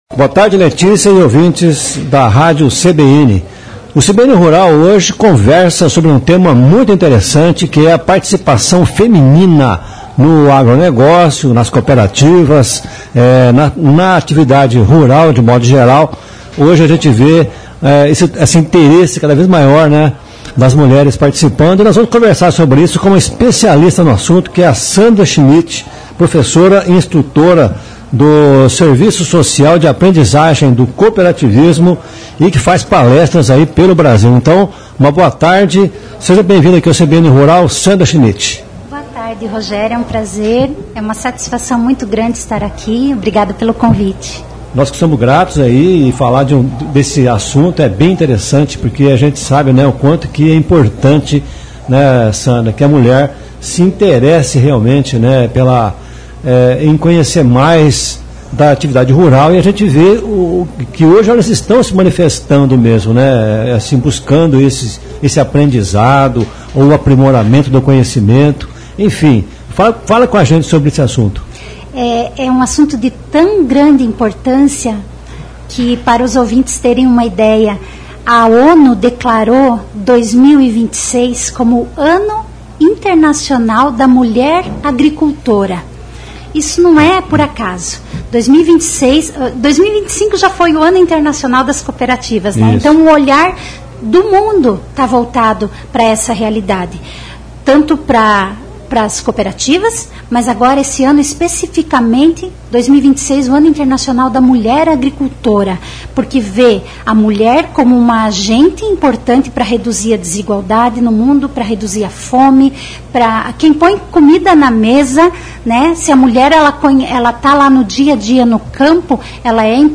Ele conversou com o CBN Rural.